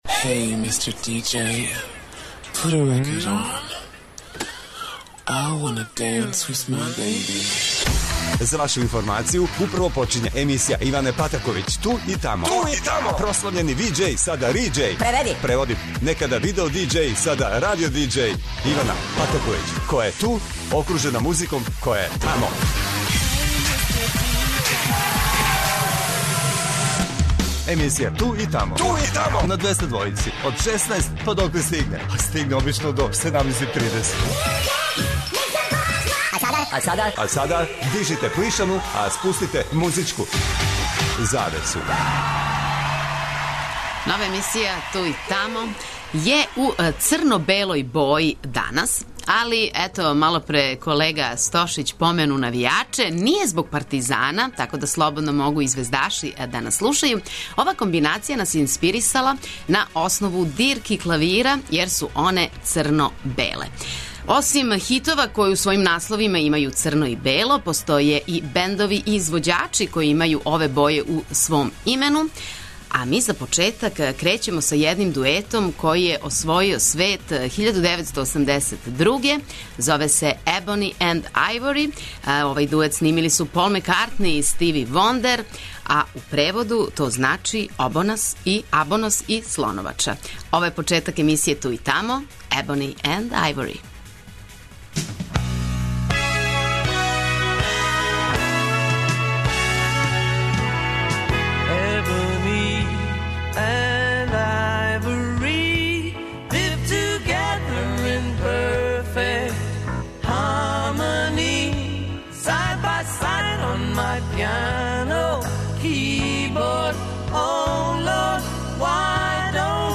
То значи да ће се завртети хитови који у насловима имају ове две боје, а на Двестадвојци ћемо слушати и извођаче који у свом имену имају црно и бело.